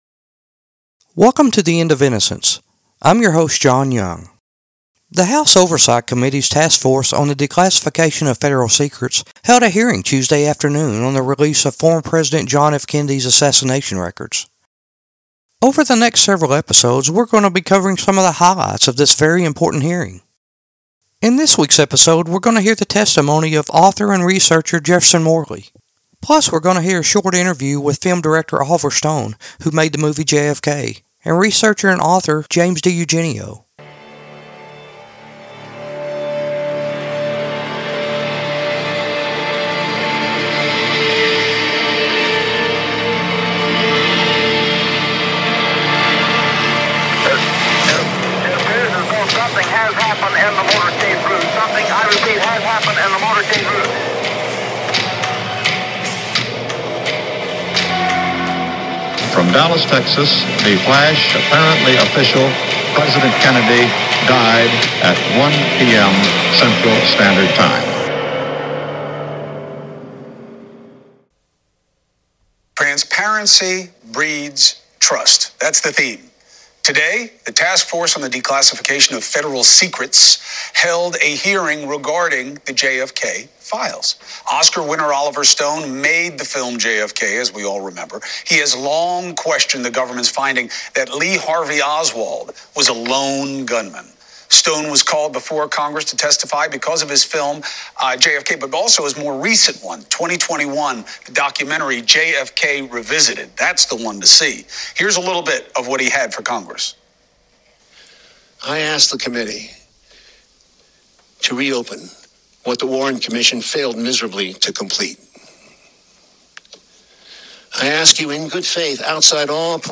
In this week's episode we hear from four doctors that treated Lee Harvey Oswald at Parkland Hospital after he was shot in the basement of the Dallas Police Department by Jack Ruby. You may be surprised to learn that Oswald didn't die from the gunshot wound he received. And did President Lyndon B. Johnson try to get a deathbed confession from Oswald as he lay dying in Parkland Hospital.